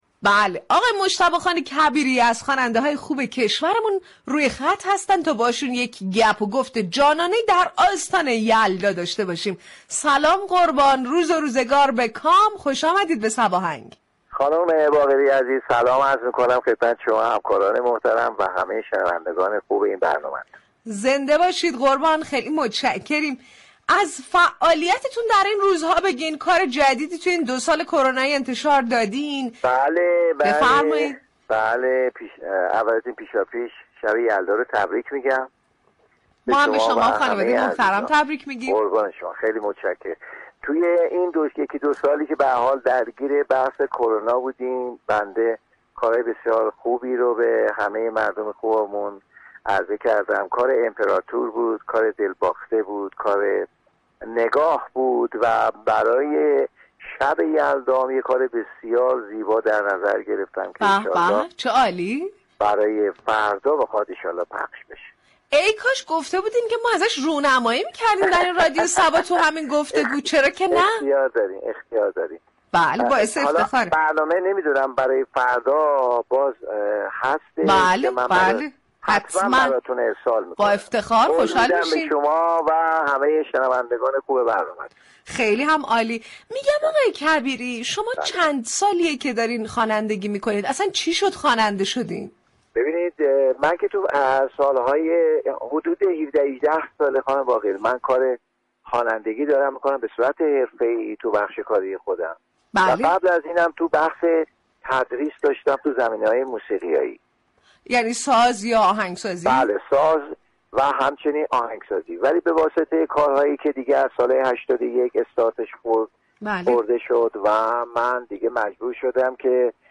به گزارش روابط عمومی رادیو صبا برنامه موسیقی محور صباهنگ هر روز از شنبه تا چهارشنبه با پخش موسیقی های درخواستی تقدیم شنوندگان آن می شود. این برنامه در بخش مهمان ویژه در هر قسمت میزبان یكی از هنرمندان عرصه موسیقی می شود.